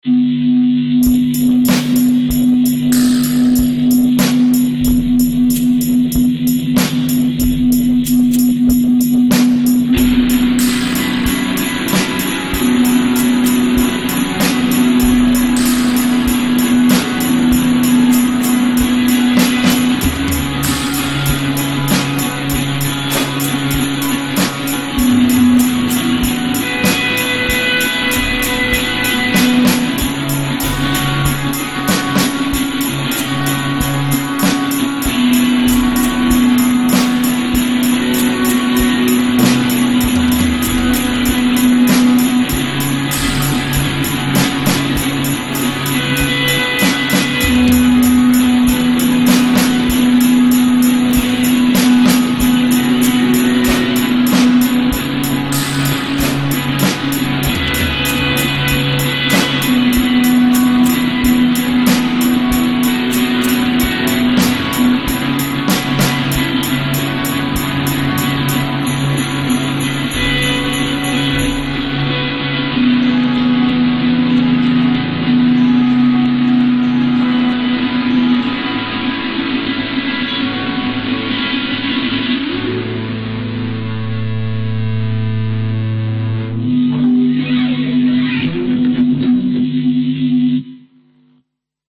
alternatív, experimentális, avantgarde, underground